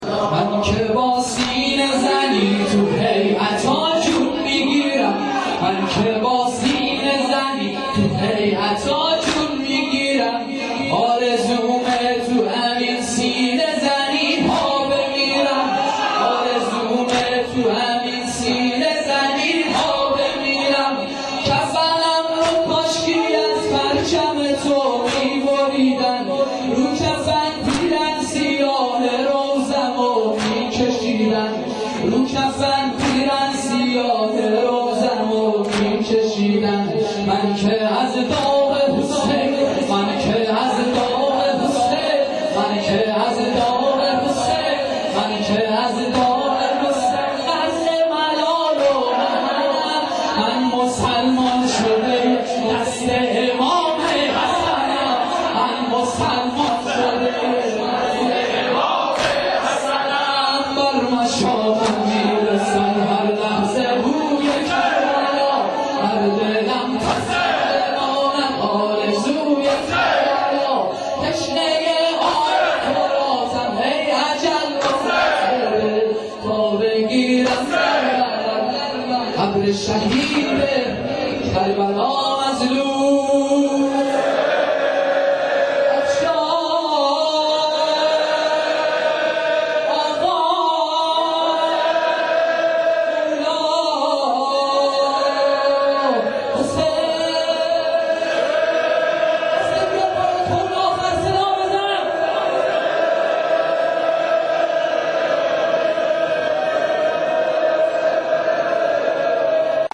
جلسه مذهبی زیارت آل یاسین باغشهر اسلامیه
سنگین - شب ششم محرم 95-06